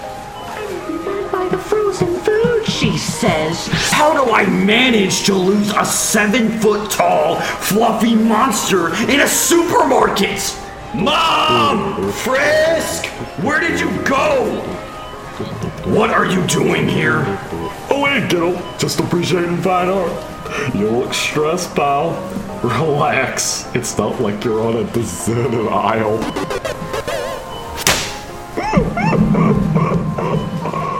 The wheeze-laugh at the end got me.